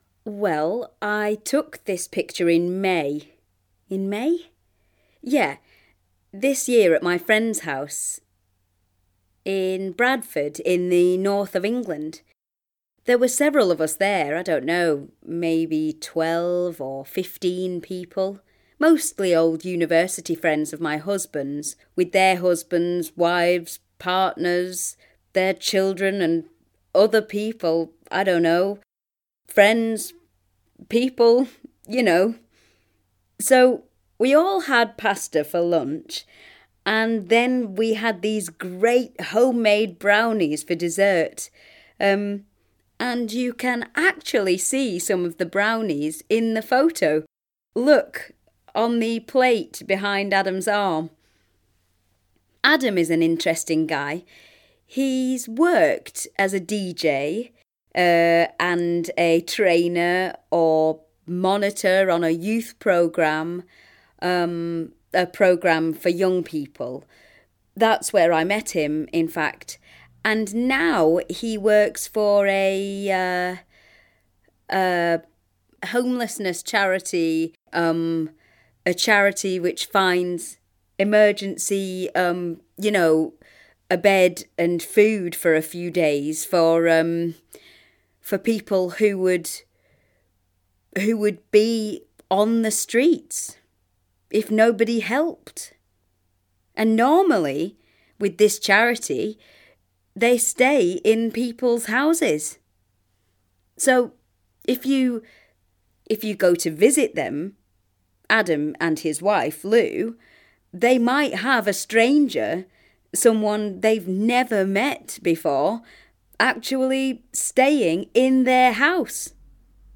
It focuses on a photograph of a tattoo of the word ‘possibilities’ on a man’s arm. In the lesson, students predict what vocabulary and information they will hear as a photographer speaks about her photo, listen to check their answers, and then write and / or perform an interview between the photographer and the person who appears in the photograph.